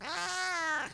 meow1.wav